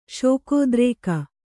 ♪ śokōdrēka